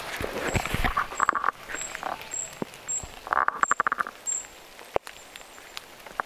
Sateessa luontopolulla kävelemässä.
Siritys oli sellaista kimeää.
toista erikoista "siritysääntä",
ti-siritystä?
tuollaista_kuin_ti-siritysta_puukiipijalinnulta.mp3